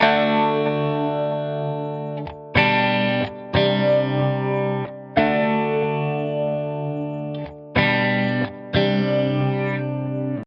闪闪发光的古拉 92 bpm
描述：用于闪闪发光的环状物
Tag: d CLOOP 古拉 回声 闪闪发光的 B